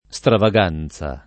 stravaganza [ S trava g# n Z a ]